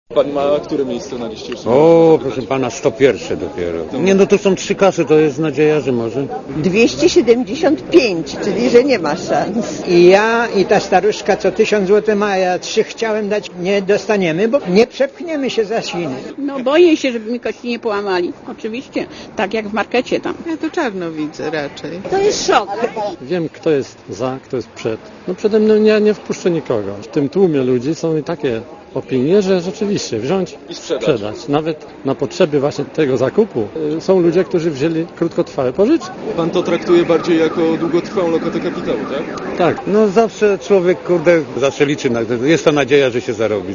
Posłuchaj, co działo się w kolejkach
kolejki_pko.mp3